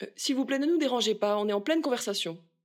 VO_ALL_EVENT_Trop proche de la cible_02.ogg